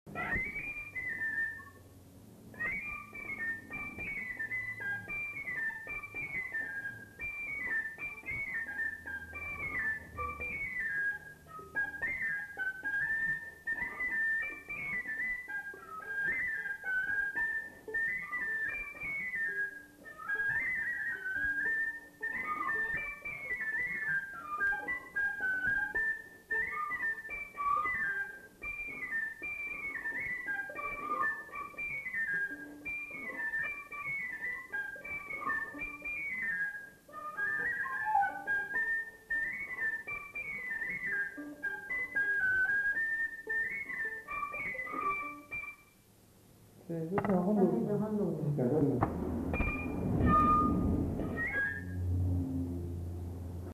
Aire culturelle : Bazadais
Lieu : Bazas
Genre : morceau instrumental
Instrument de musique : fifre
Danse : rondeau